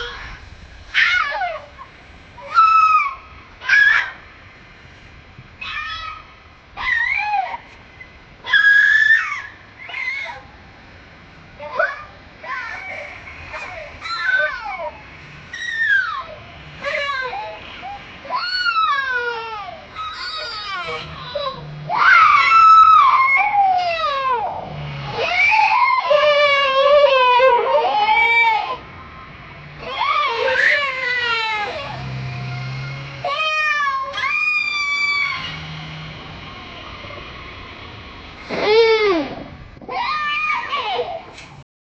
dinosaur-roaring-child-sc-akabvxar.wav